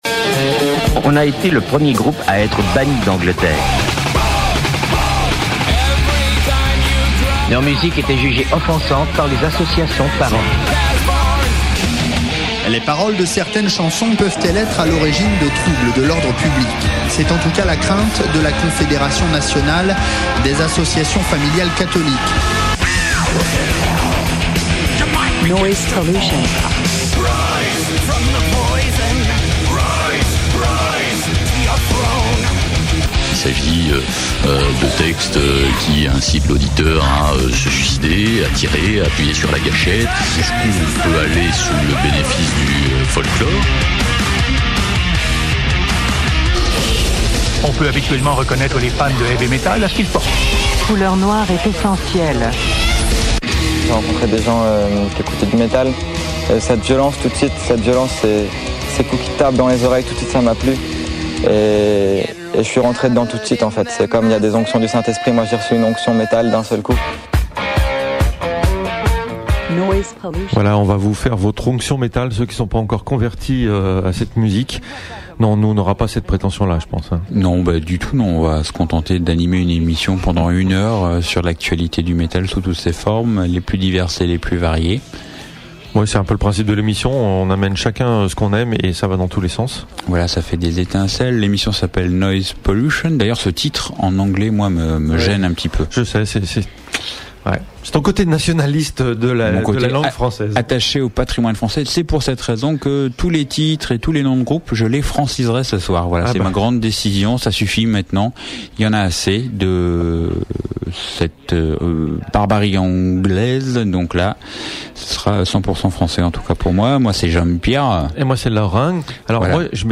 Noise Pollution - l'émission metal de Radio Canut (Lyon 102.2FM)